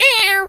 bird_tweety_hurt_06.wav